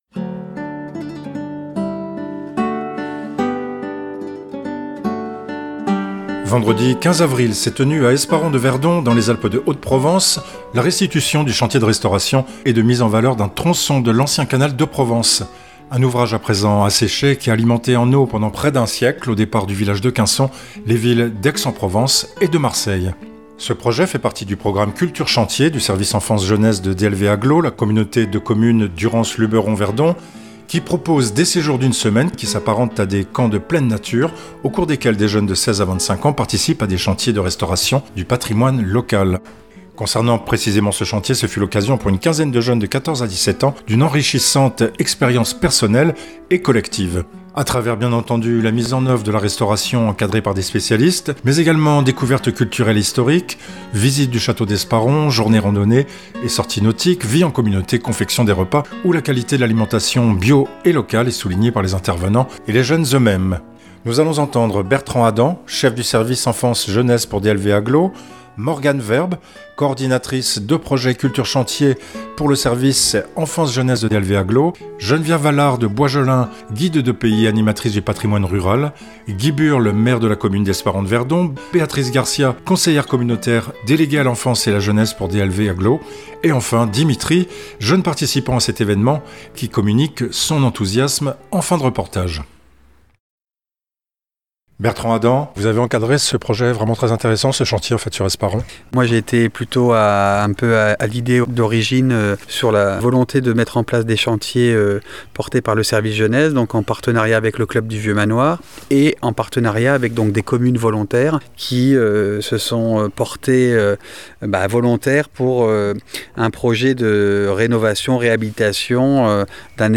Reportage
Musique : Jean-Sébastien Bach - Partita #1 en Si bémol majeur BWV 825 - Guitare